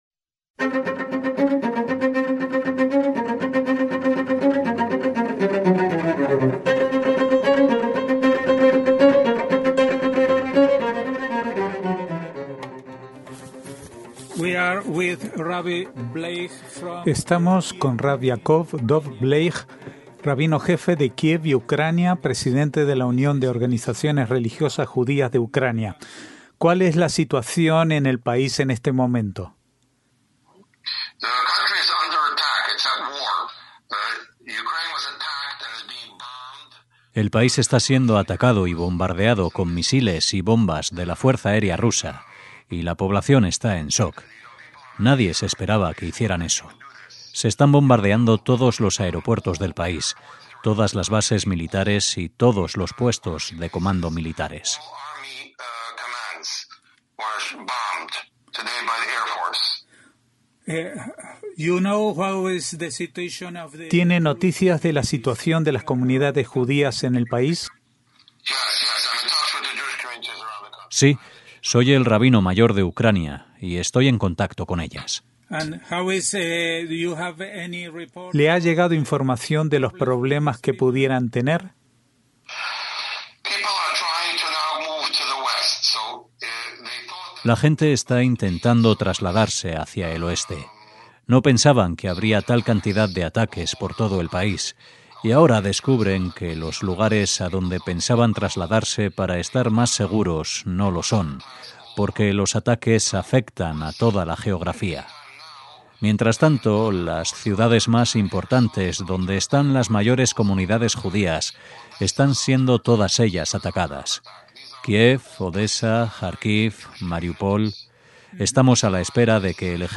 CASI EN DIRECTO - Hablamos con rab Yaakov Dov Bleich, rabino jefe de Kiev y Ucrania, Presidente de la Unión de Organizaciones Religiosas Judías de Ucrania. ¿Cuál es la situación en el país en este momento?